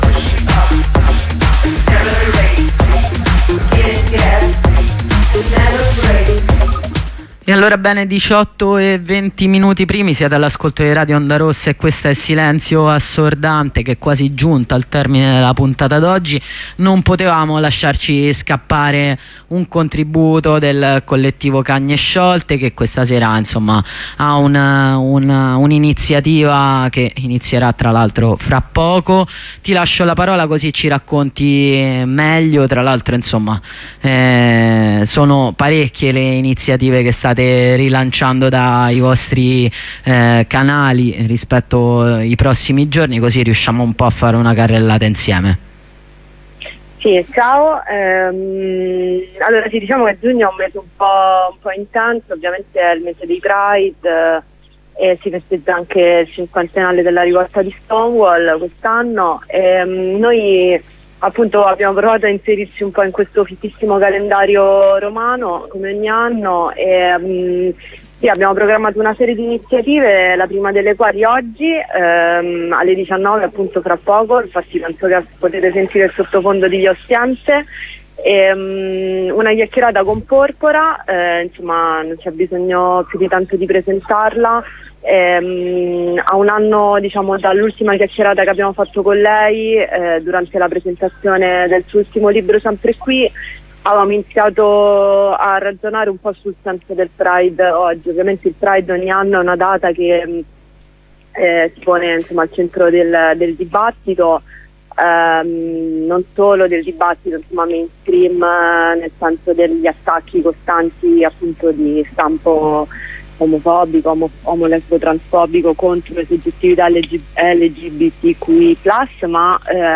corrispondenza da budapest